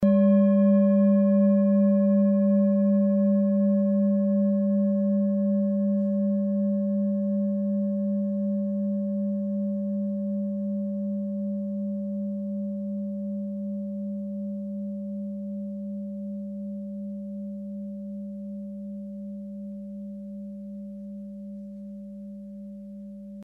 Klangschale Orissa Nr.17
Sie ist neu und wurde gezielt nach altem 7-Metalle-Rezept in Handarbeit gezogen und gehämmert.
Die Pi-Frequenz kann man bei 201,06 Hz hören. Sie liegt innerhalb unserer Tonleiter nahe beim "Gis".
klangschale-orissa-17.mp3